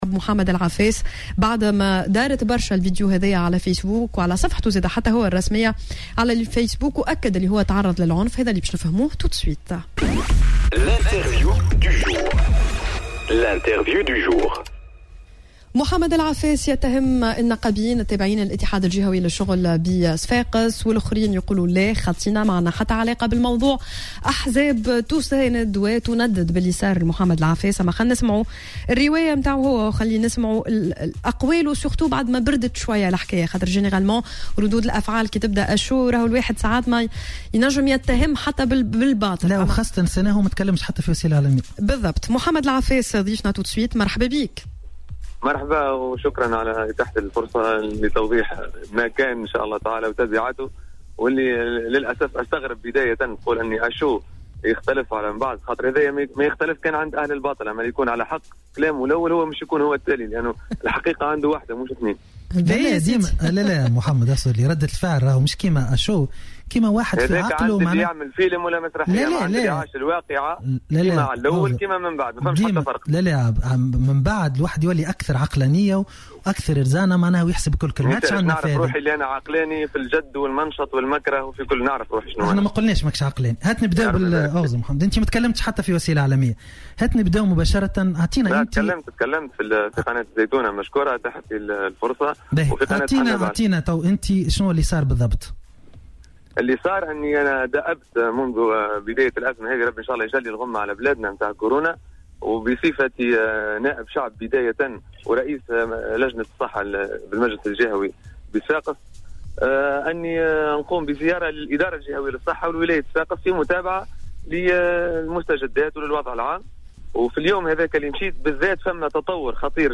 وقال في مداخلة هاتفية مع "صباح الورد" على "الجوهرة اف أم" أن الحادثة تزامنت مع انتشار خبر احتمال تعرض الطاقم الصحي بمستشفى صفاقس بعدوى كورونا من مريض بالمستشفى.